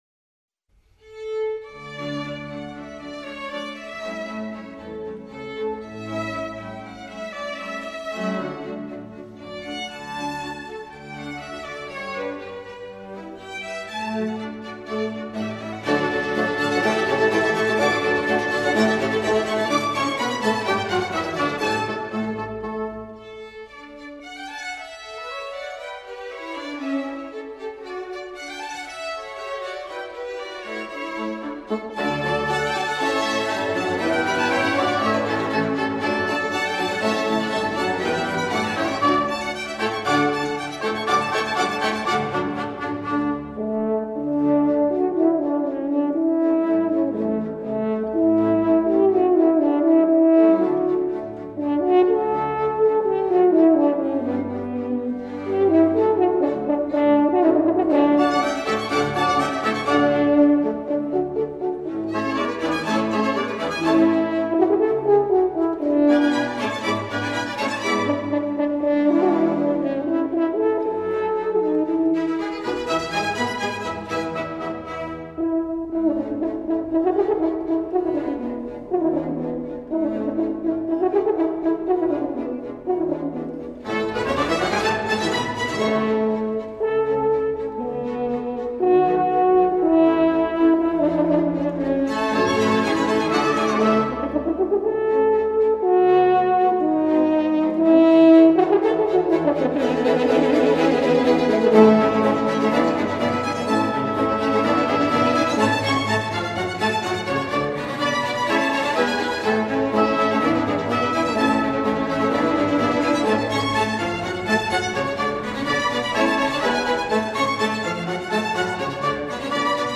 第23#钢琴协奏曲A大调
慢板
谢谢楼主....轻快极适合小朋友